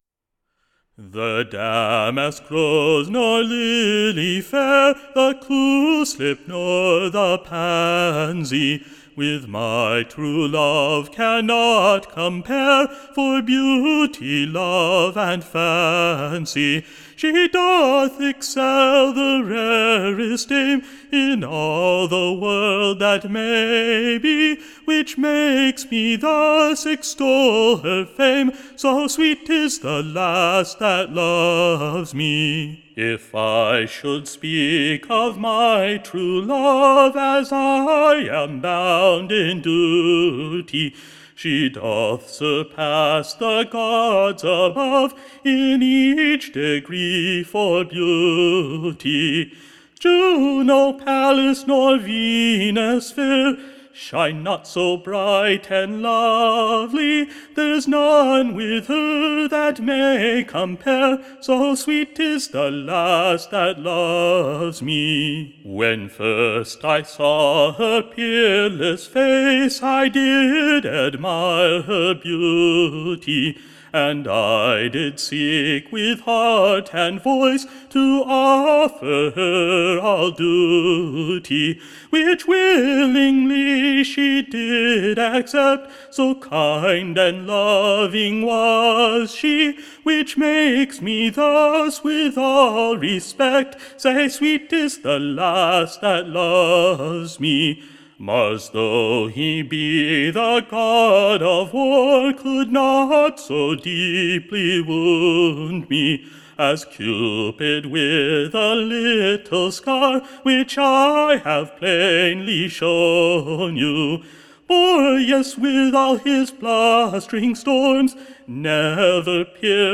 Audio Companion to "The Broadside Ballad in Early Modern England"
sung to “Damask Rose